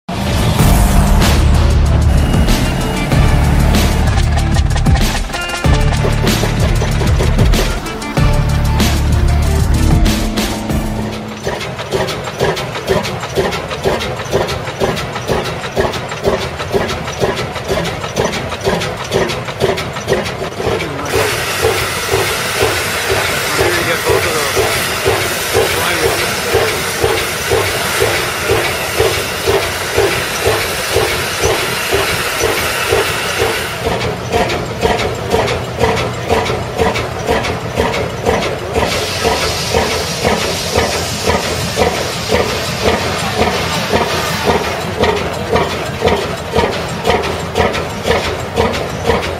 Big Crazy Old Engines Start sound effects free download
Big Crazy Old Engines Start Up Sound